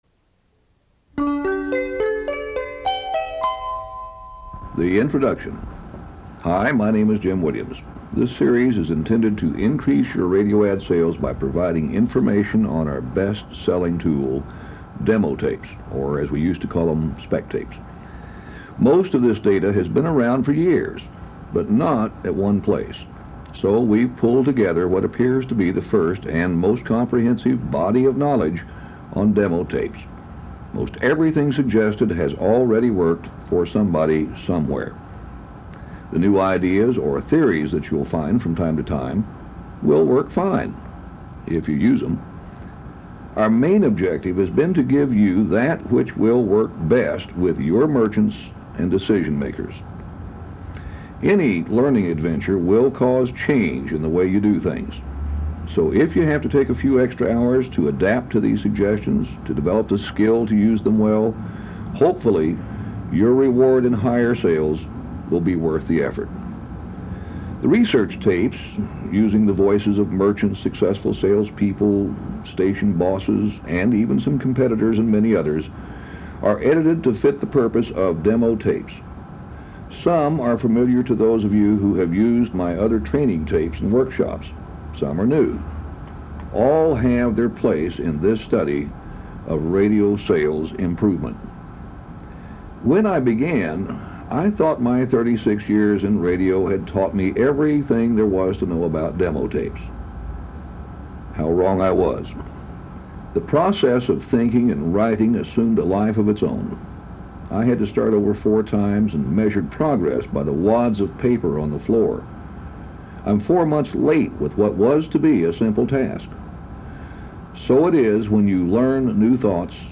Before listening to this six-minute presentation, please be forewarned: the quality of the audio isn’t anything to write home about.  It wasn’t state-of-the-art even back then.
You will hear hiss on the tape, street traffic noise in the background, and will have to put up with occasional distractions.